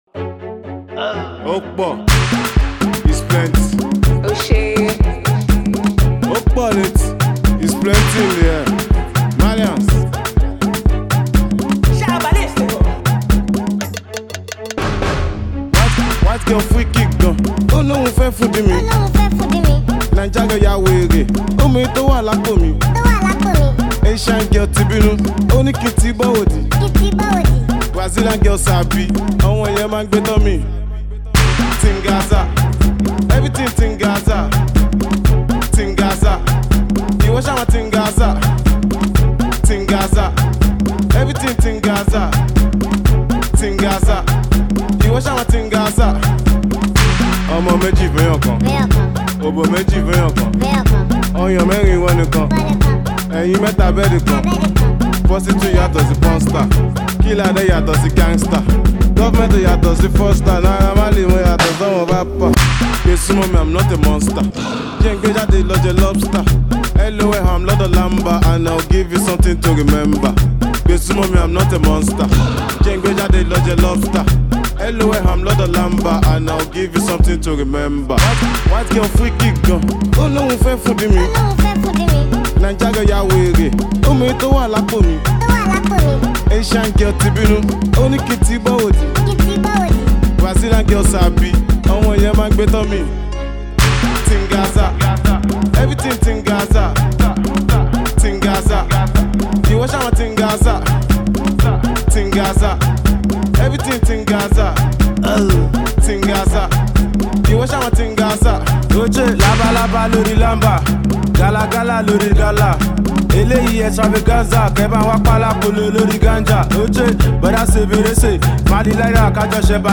Nigerian Afrobeats singer-songwriter and Indigenous rapper